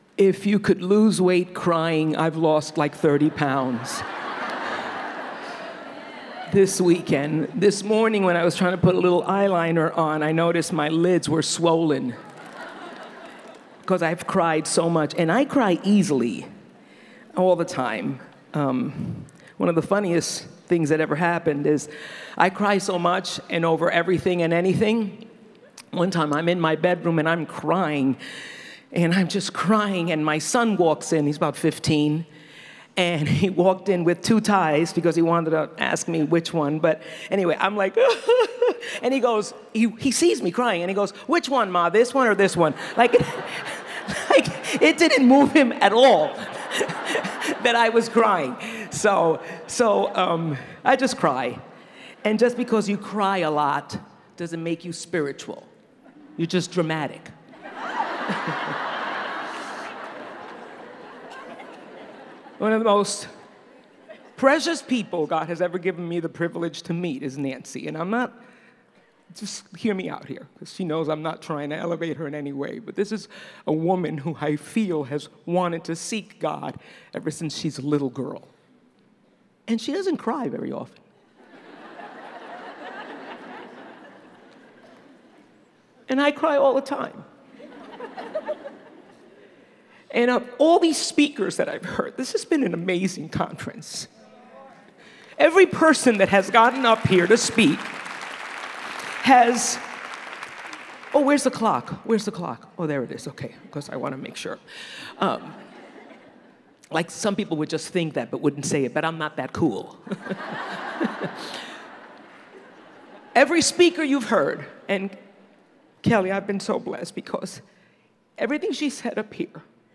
The Personal Devotional Life | Revive '19 | Events | Revive Our Hearts
Learn how to become a woman who loves the Word in this helpful message.